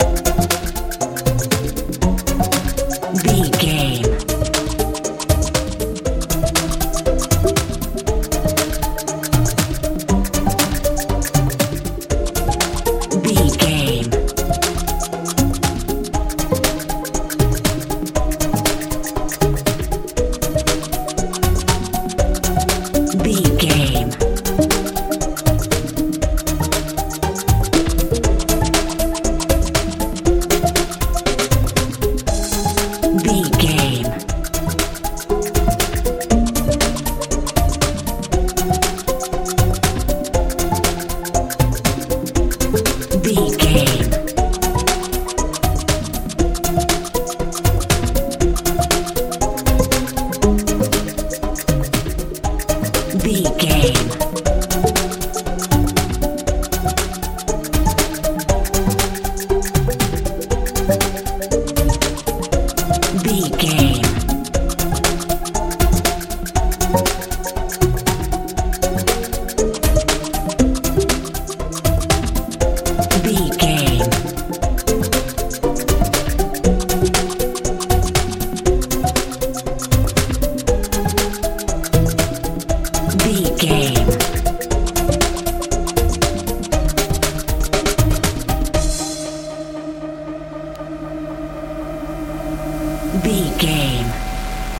modern dance feel
Ionian/Major
bouncy
dreamy
bass guitar
drums
synthesiser
80s
mechanical